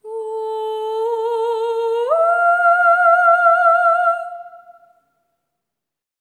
ETHEREAL05-R.wav